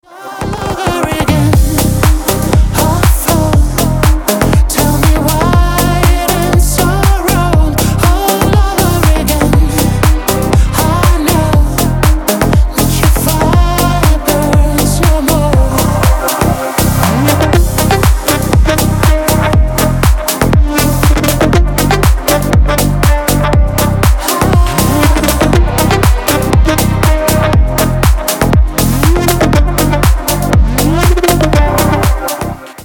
Клубные песни на рингтон